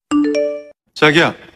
Nada notifikasi Korea Chagiya suara cowok
Kategori: Nada dering
nada-notifikasi-korea-chagiya-suara-cowok-id-www_tiengdong_com.mp3